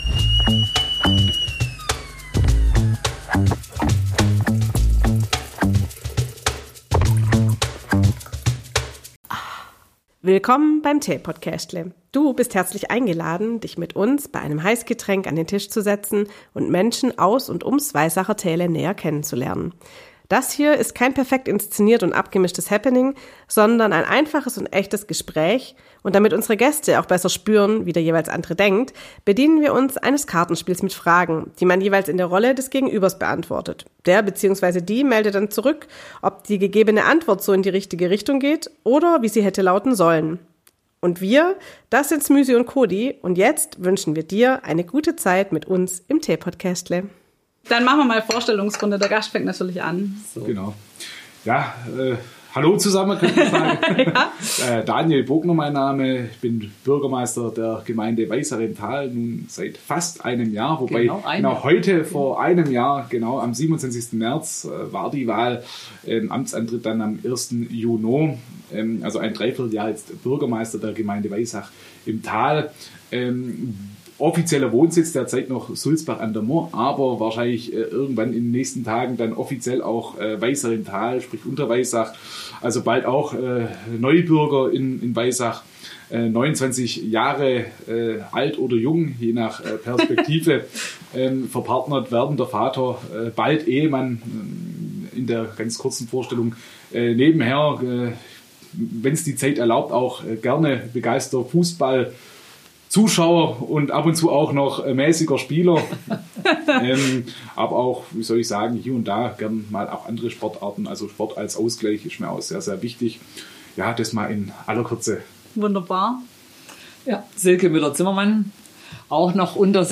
Was haben eine Einzelhändlerin und ein Bürgermeister gemeinsam? Finden wir es in unserer heutigen Folge heraus, indem die Gesprächspartner jeweils aus der Perspektive des anderen Fragen beantworten.